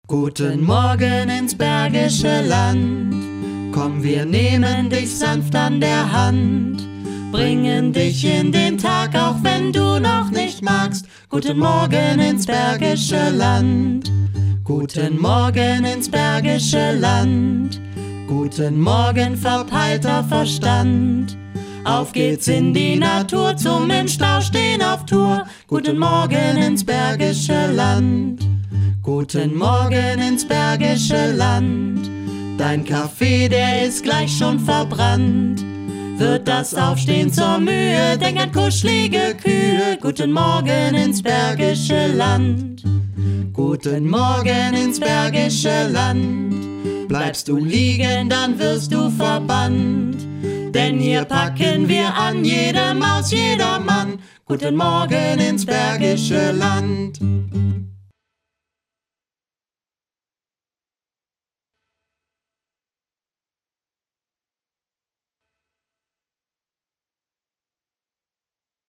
Sanfter Wecker
sanfter-wecker.mp3